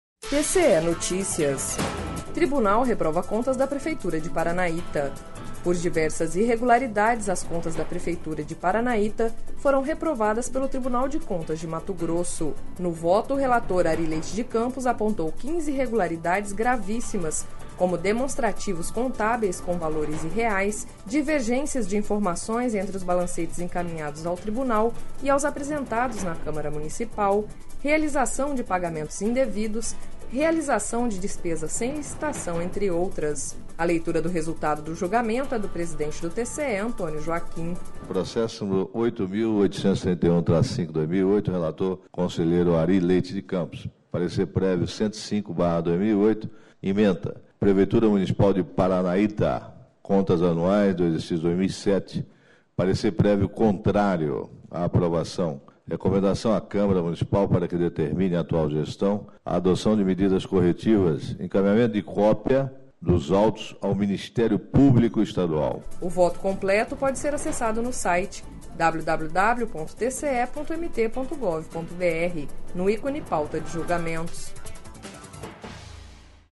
A leitura do resultado do julgamento é do presidente do TCE-MT, Antonio Joaquim.